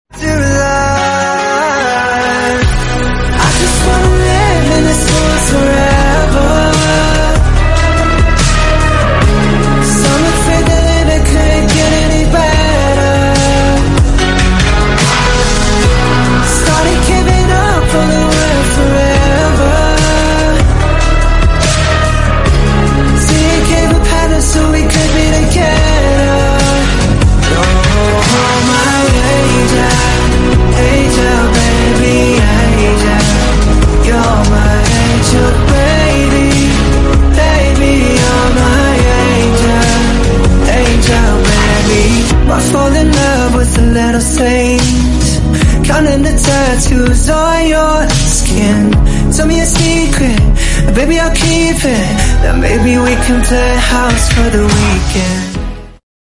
I used my own voice model to make this cover.